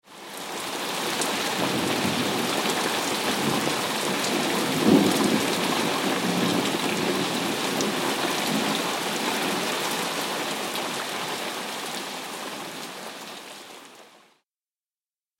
دانلود آهنگ رعد و برق 2 از افکت صوتی طبیعت و محیط
دانلود صدای رعد و برق 2 از ساعد نیوز با لینک مستقیم و کیفیت بالا
جلوه های صوتی